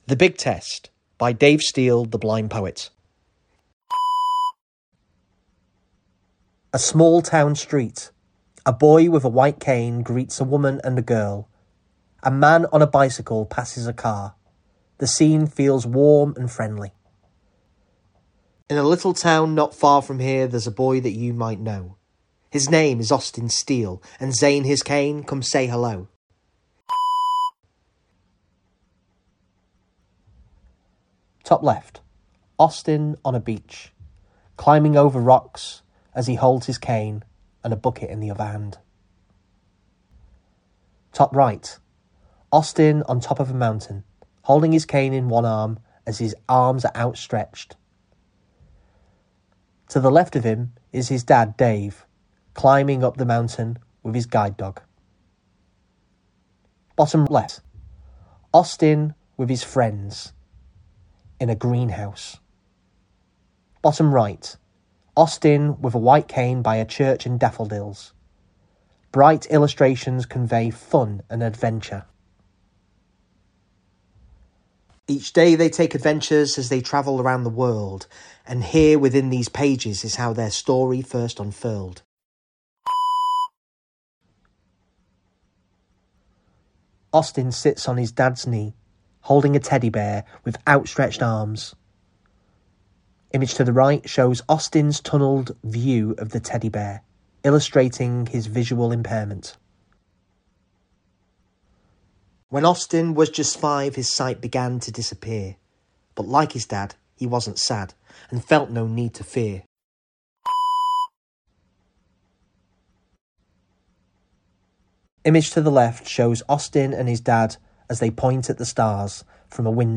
Book Mp3 File
Then a 'beep' will signal you to turn the page.